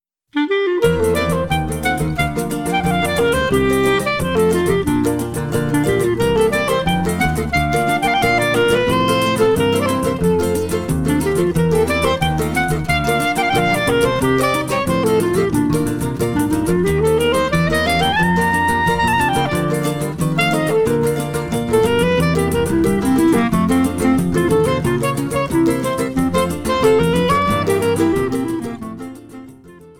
clarinet
Choro ensemble